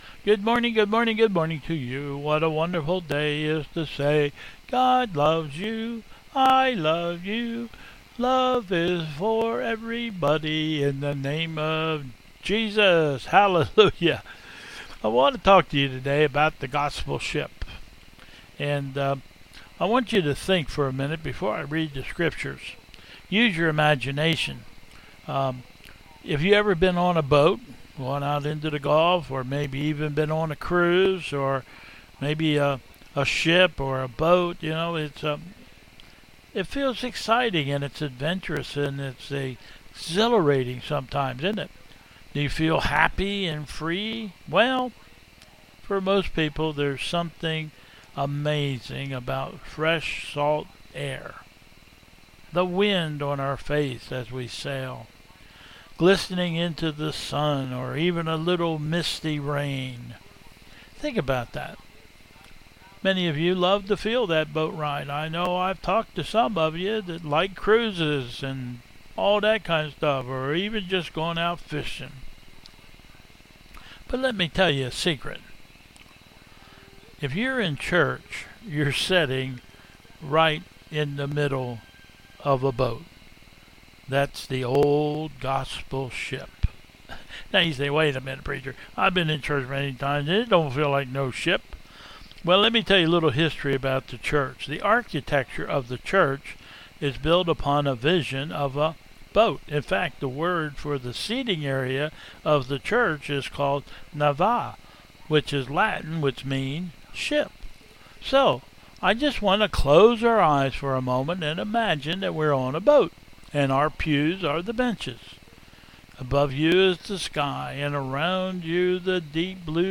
"Youth" Message : " The Gospel Ship " . (13:28) Though addressed to the "Youth", whom we truly miss seeing on Sundays, this message is for all of us.